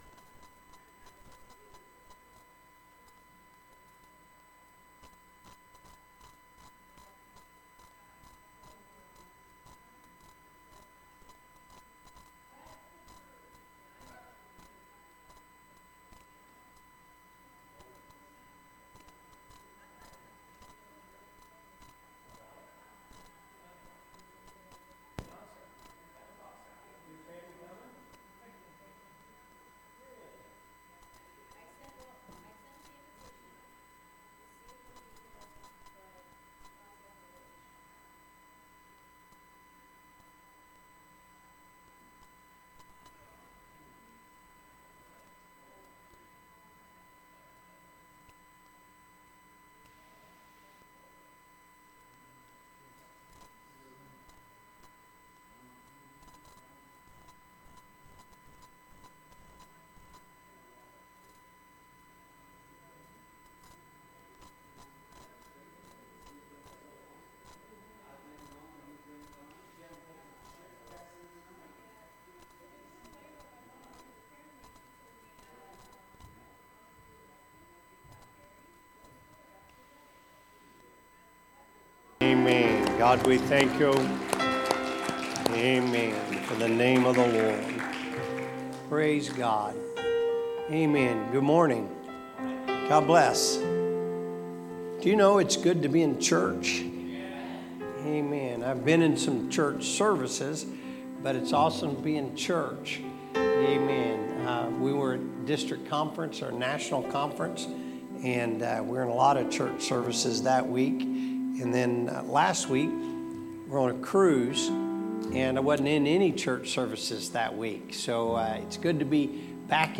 Sermons | Elkhart Life Church
Sunday Service - Part 13